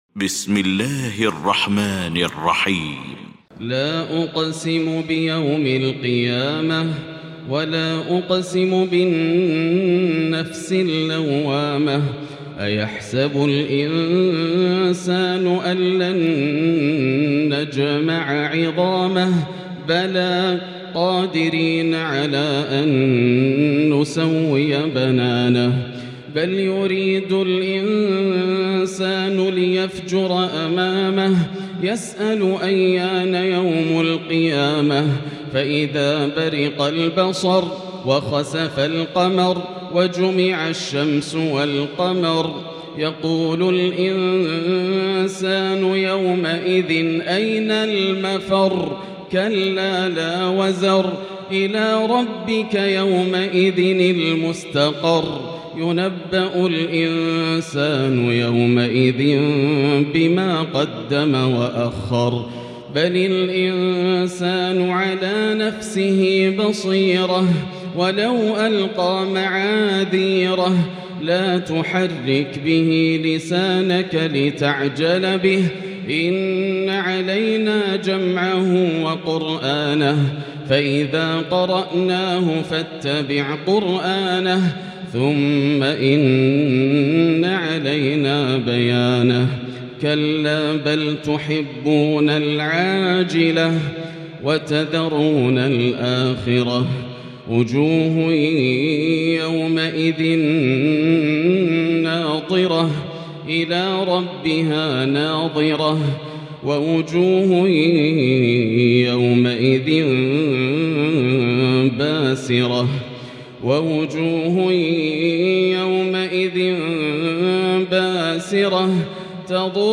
المكان: المسجد الحرام الشيخ: فضيلة الشيخ ياسر الدوسري فضيلة الشيخ ياسر الدوسري القيامة